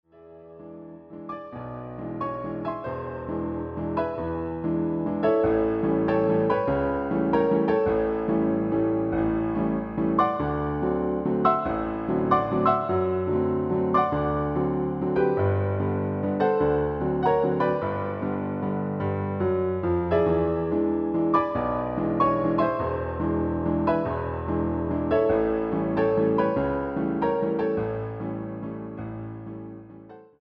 Quality mp3 file recorded from the original piano roll